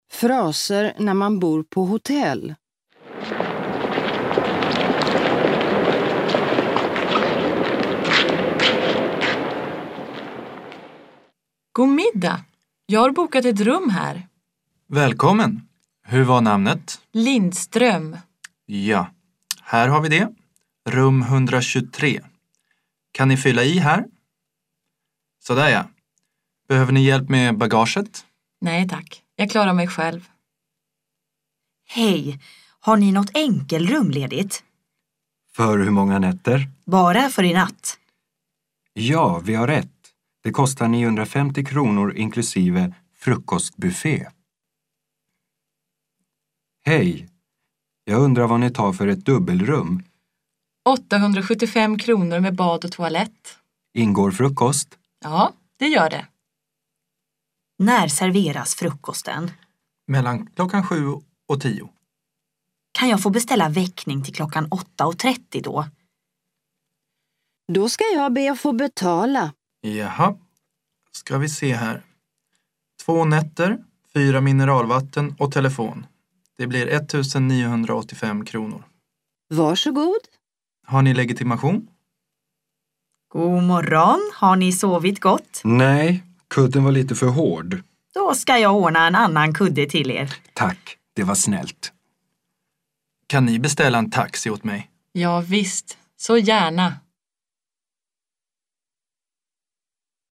Luisteroefening